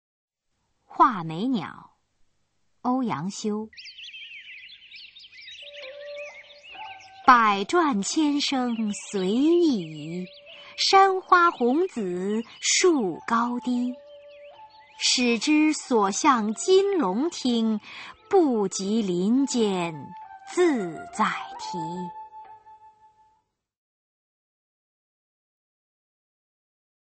[宋代诗词朗诵]欧阳修-画眉鸟 古诗词诵读